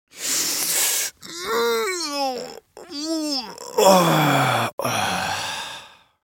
دانلود آهنگ بیدار شدن از افکت صوتی طبیعت و محیط
دانلود صدای بیدار شدن از ساعد نیوز با لینک مستقیم و کیفیت بالا
جلوه های صوتی